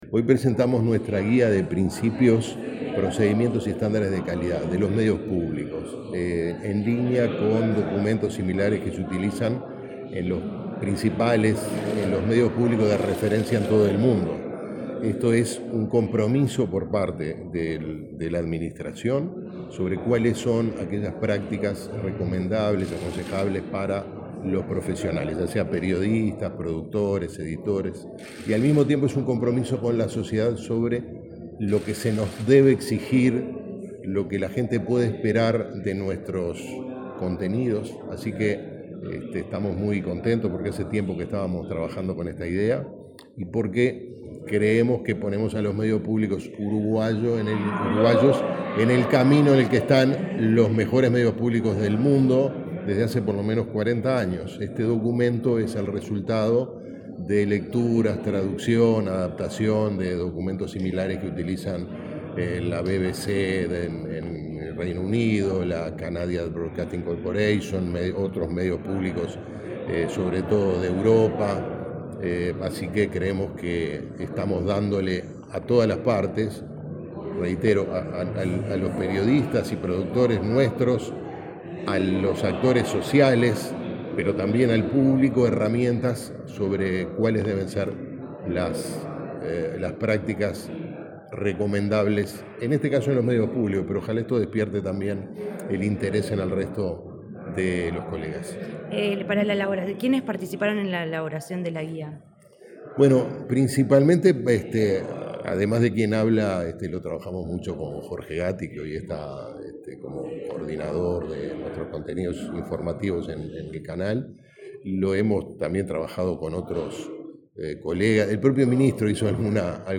Entrevista al presidente del Secan, Gerardo Sotelo